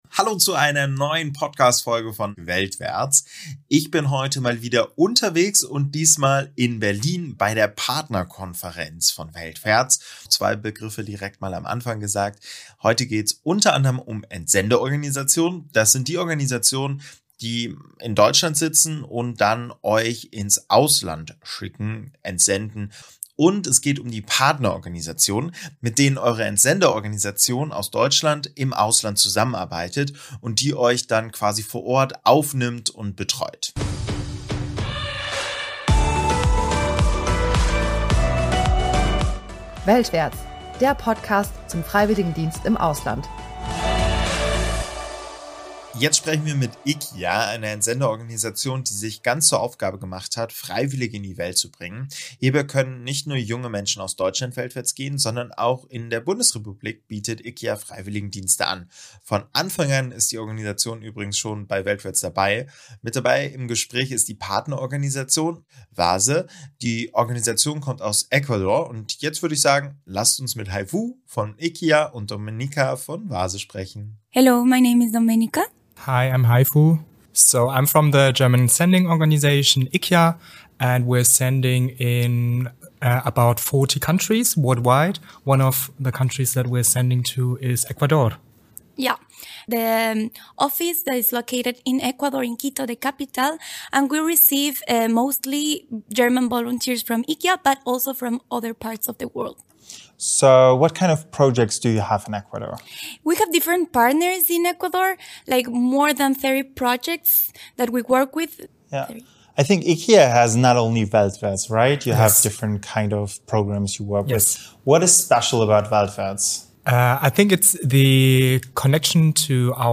Beschreibung vor 2 Jahren Auf der mehrtägigen internationalen Partnerkonferenz kamen im Herbst in Berlin die verschiedenen weltwärts-Akteure zusammen, um sich auszutauschen und zu vernetzen.
Worauf legen beide bei der Zusammenarbeit mit Freiwilligen wert und welche Tipps haben sie für diese? All das hört ihr in dieser Folge von Mitarbeitenden deutscher Organisationen und ihren Partnerorganisationen.